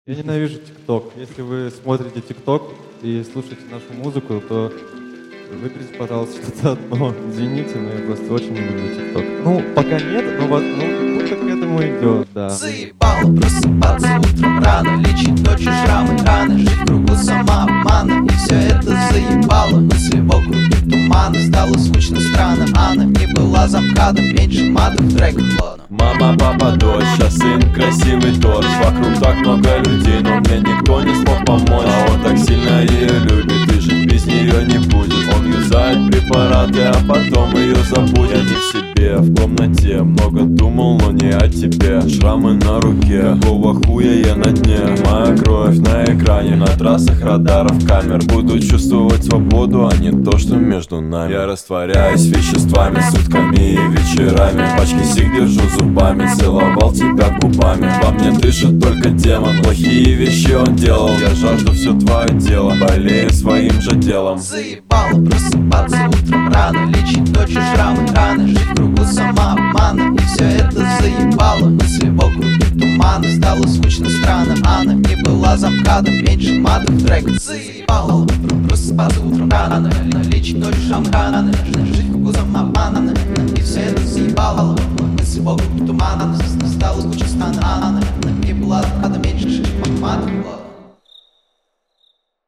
Качество: 320 kbps, stereo
Рэп